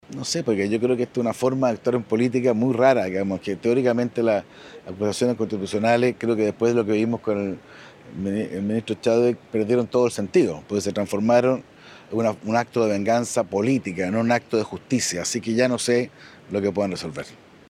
En tanto, el senador de la UDI, Juan Antonio Coloma, enfatizó que la oposición transformó las acusaciones constitucionales en un acto de venganza política.